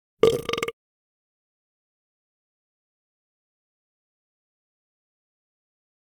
petburp.ogg